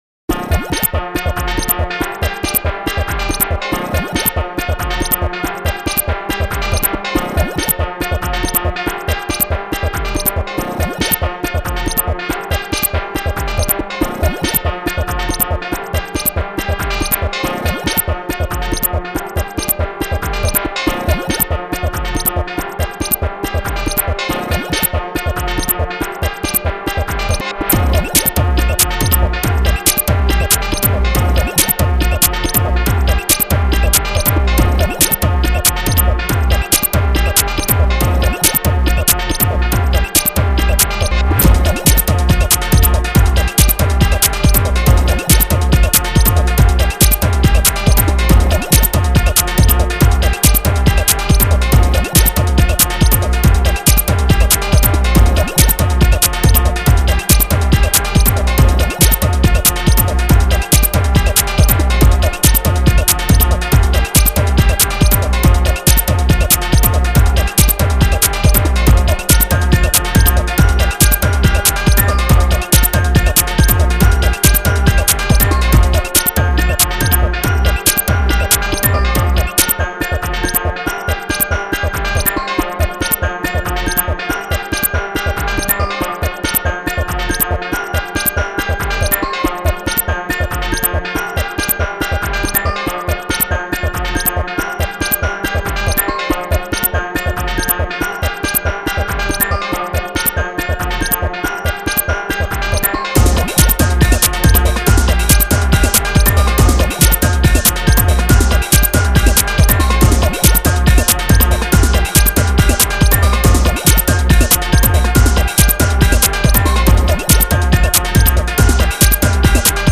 September 16, 2014 / / experimental